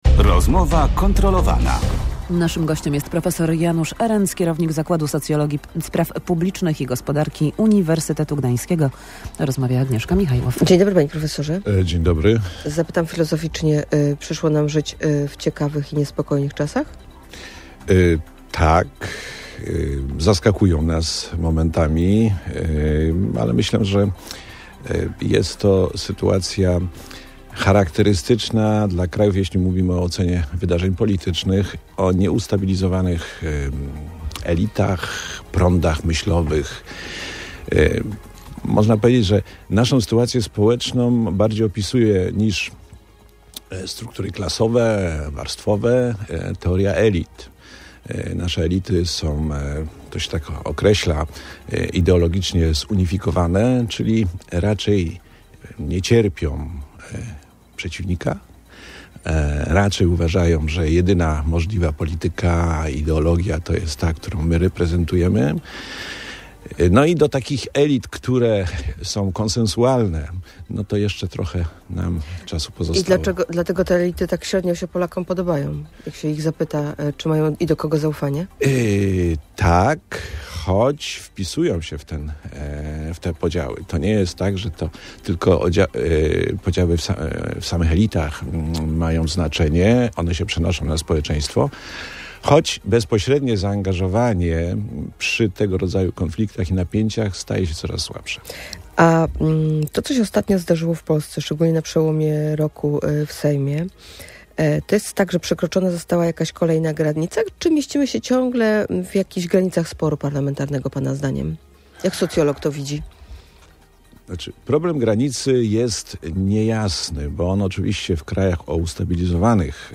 W Rozmowie kontrolowanej Radia Gdańsk ekspert wyjaśnia, że polska dopiero się buduje.